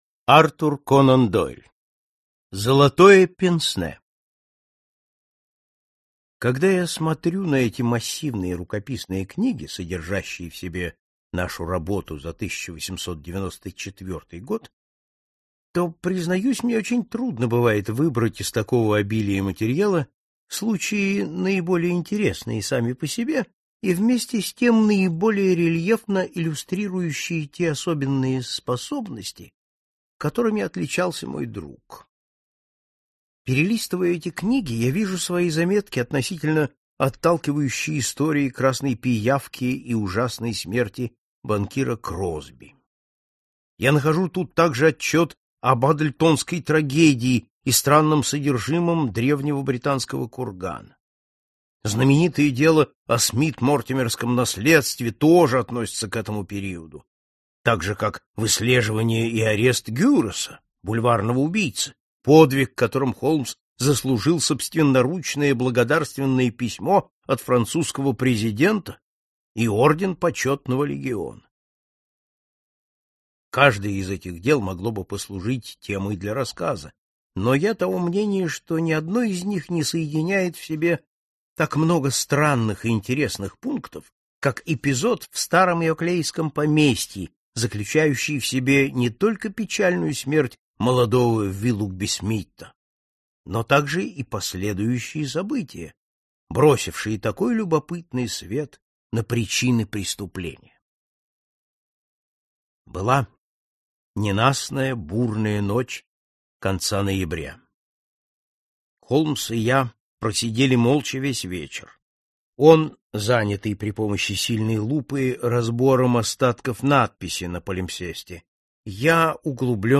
Аудиокнига Классика зарубежного детективного рассказа 2 | Библиотека аудиокниг